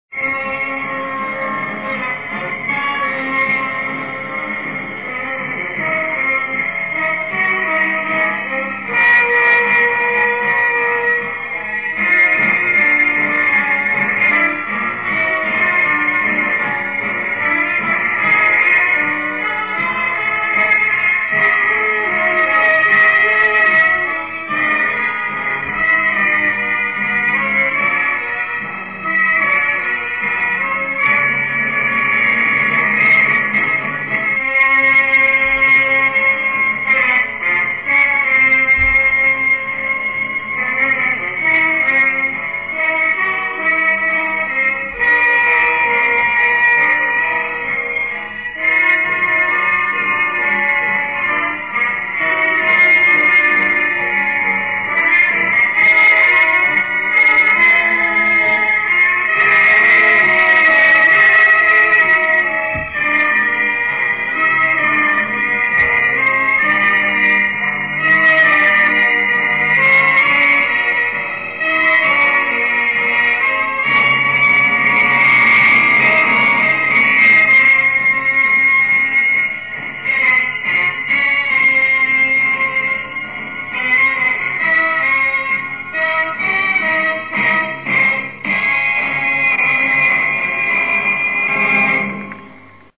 A version of the closing theme.